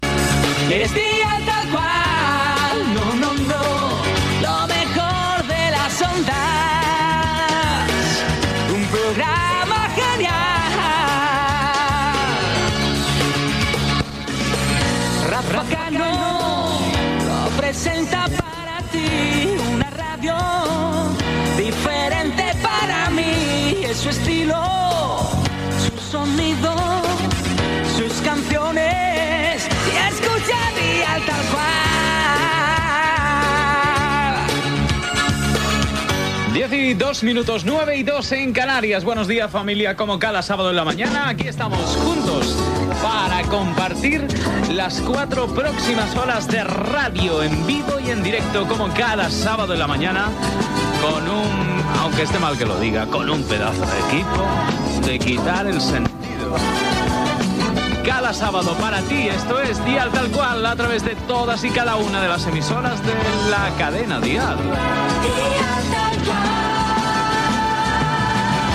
Careta del programa, hora, presentació
Musical
FM